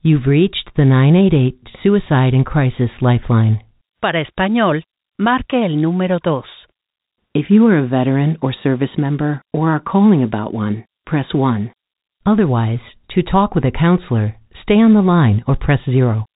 message that gives you service selection options including Veterans (Press 1), Spanish-language (Press 2), or your local 988 Lifeline contact center.